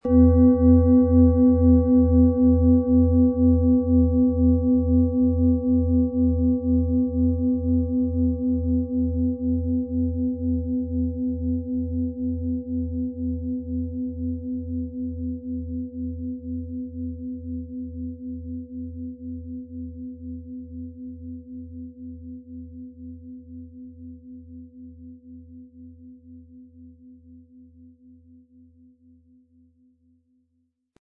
XL Kinder-Fußreflexzonen-Klangschale mit Planetenton Sonne
Der Klang des Planetentons Sonne schenkt sanfte, aber kraftvolle Schwingungen, die den Körper durchströmen, Spannungen lösen und das innere Strahlen des Kindes fördern.
Diese Klangschale erzeugt eine sanfte, aber kraftvolle Resonanz, die beruhigend auf das Nervensystem wirkt, beim Einschlafen hilft und ein Gefühl von innerer Stärke und Geborgenheit schenkt.
Wie klingt diese Planetenschale® Sonne?
Um den Originalton der Schale anzuhören, gehen Sie bitte zu unserer Klangaufnahme unter dem Produktbild.